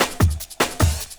50LOOP01SD-R.wav